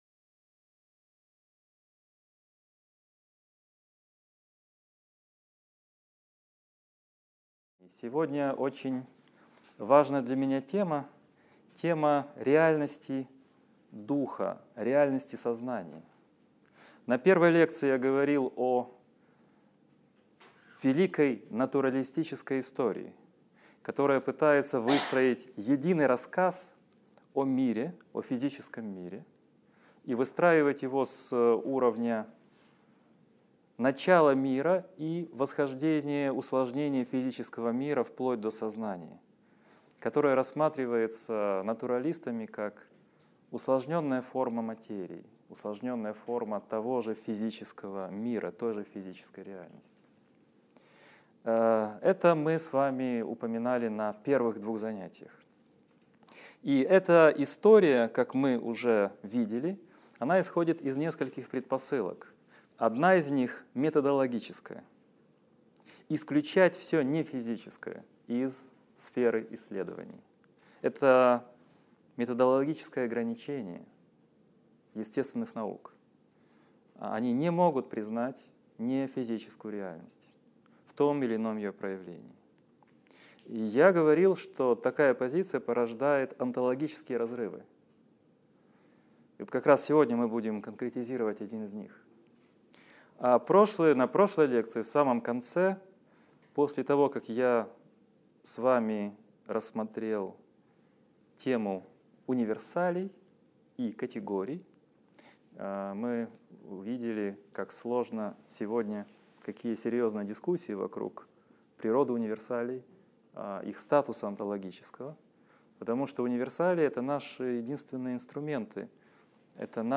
Аудиокнига Лекция 14. Реальность духа: что такое сознание | Библиотека аудиокниг